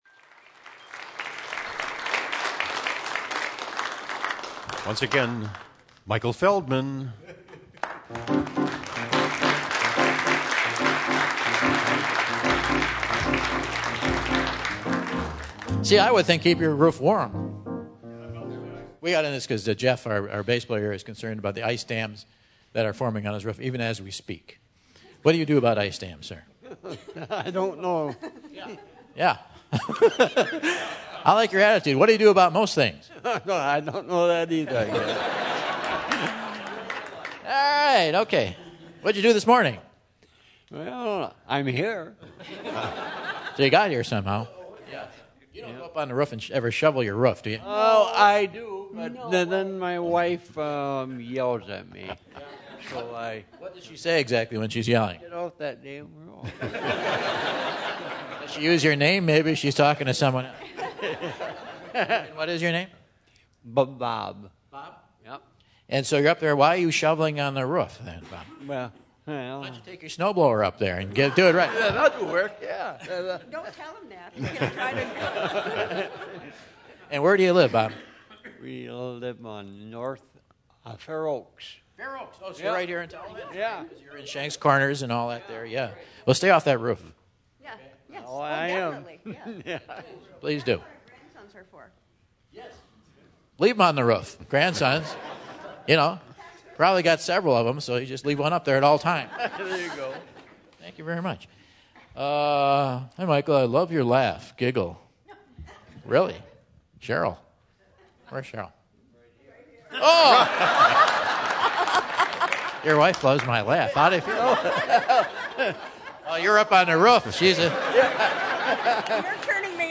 After some sass from the crowd, Michael launches into another round of the Quiz!